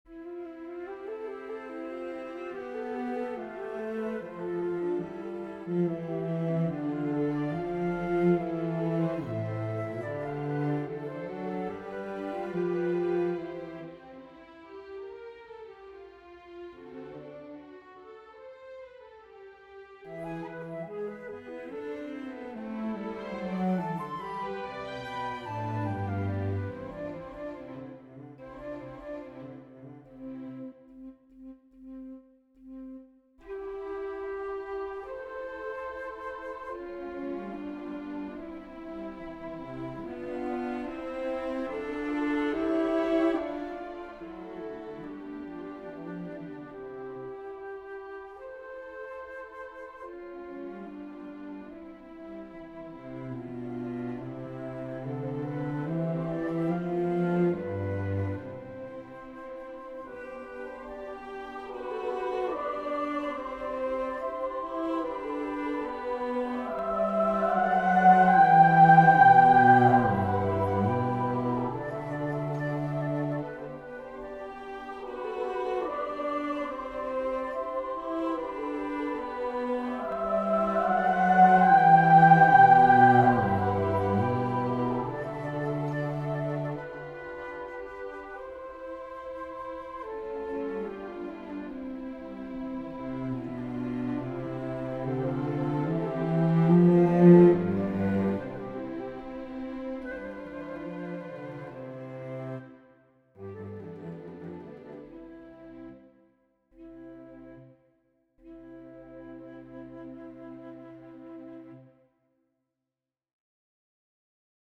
The Mass is a traditional musical form that challenges the composer to compose for instruments, choir, and solos at the same time.